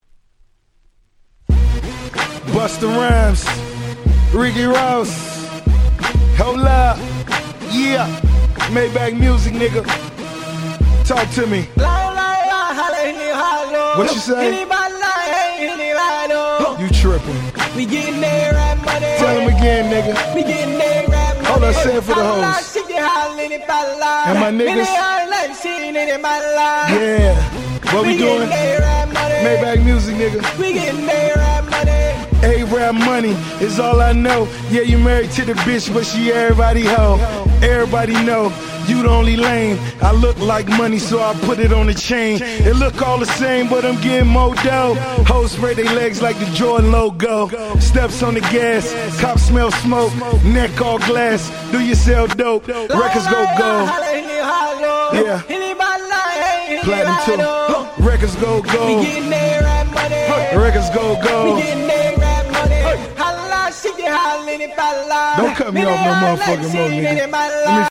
08' Big Hit Hip Hop !!!!!